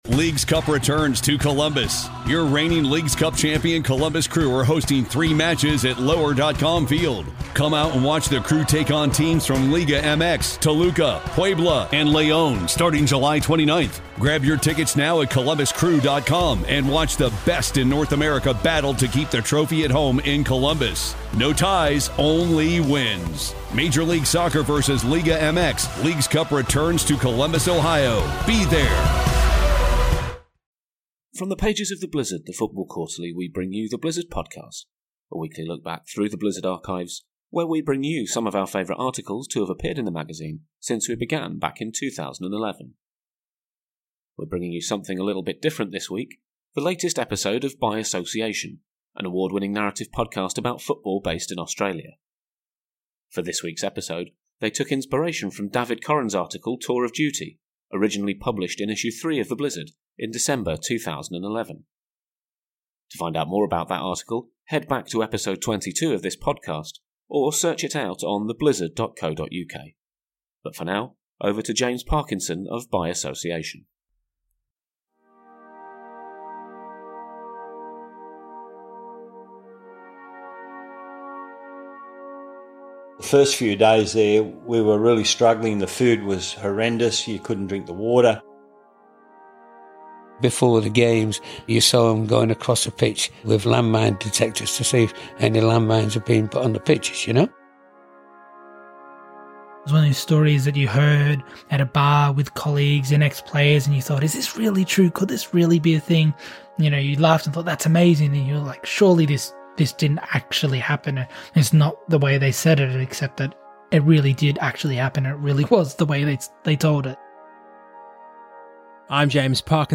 For this week’s episode we present the most recent offering from By Association, an award-winning narrative podcast about football, based in Australia.